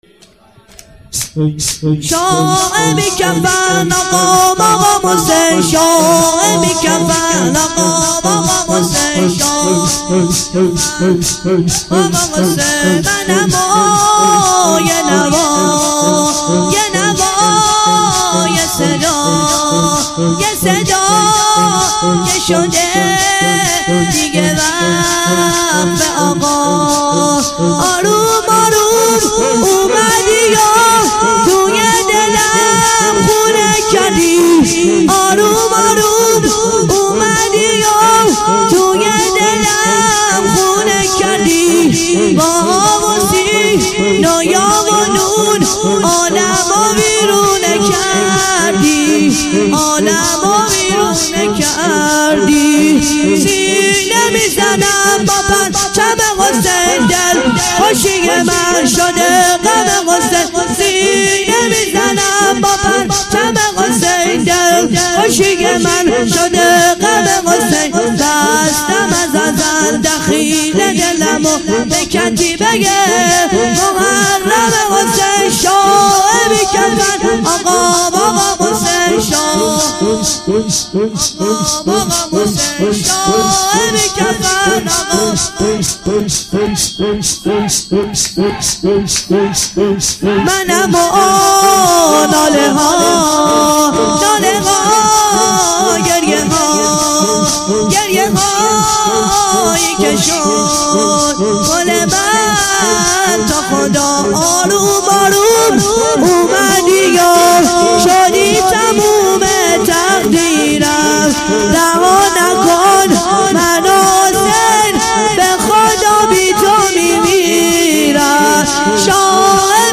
شور - من و یه نوا یه نوا یه صدا